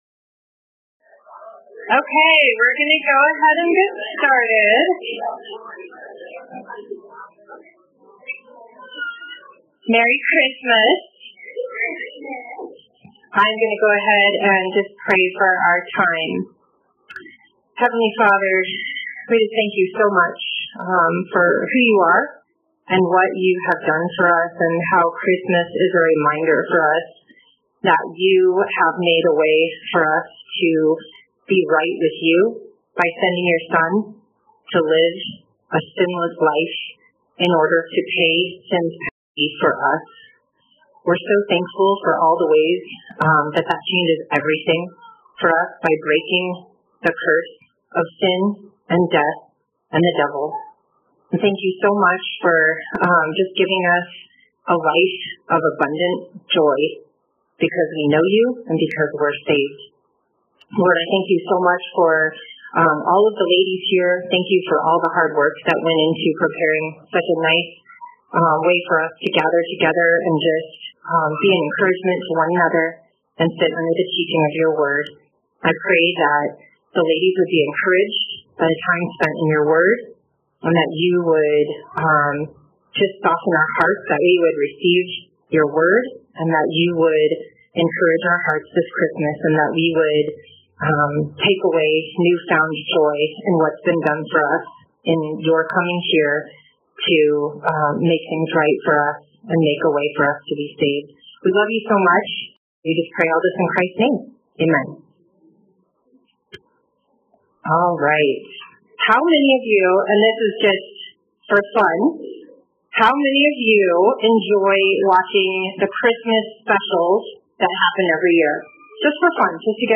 [women’s christmas tea audio] Luke 2:8-20 The Greatest Story Ever Told | Cornerstone Church - Jackson Hole